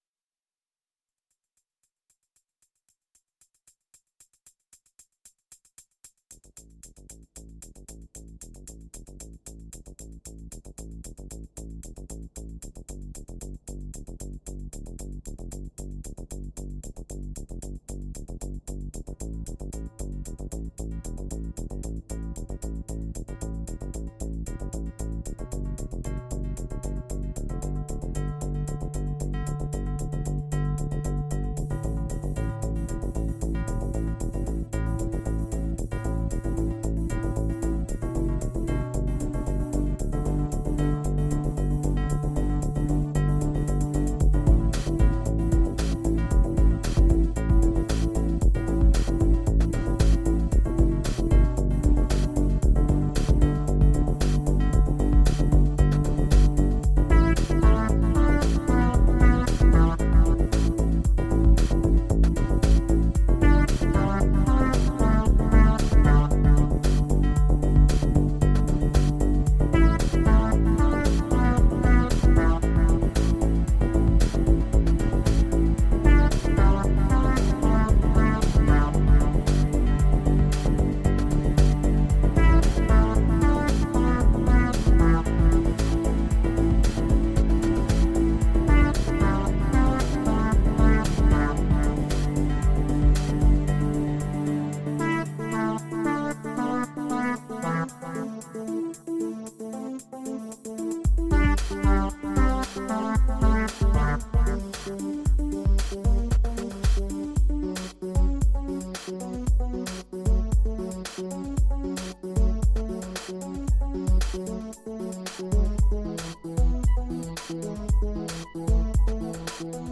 Atmospheric downtempo.
Tagged as: Ambient, Electronica, IDM, Space Music